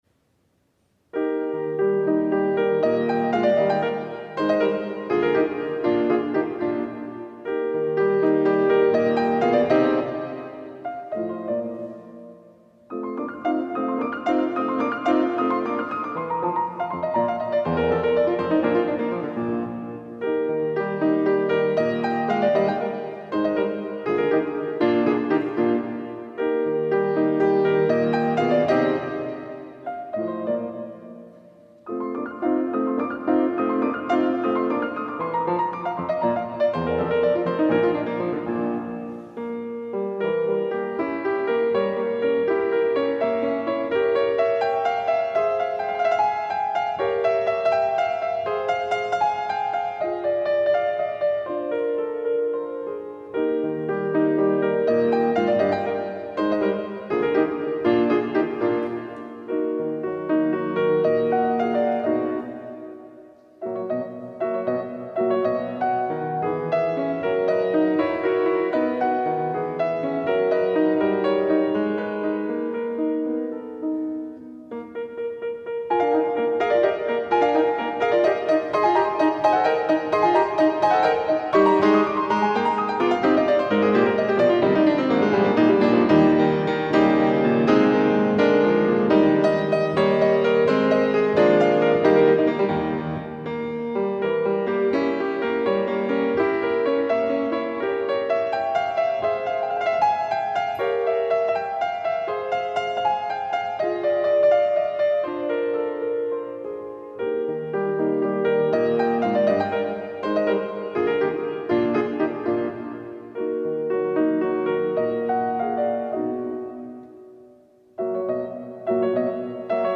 17° Concerto Musica Classica – venerdì 24 novembre 2017 alle ore 20:45 tenuto nella “Sala dei Giganti” dell’Università degli Studi di Padova a Palazzo Liviano (Padova).
L.v.Beethoven – Grande Sonata in mi bemolle maggiore op.7
Allegro
pianoforte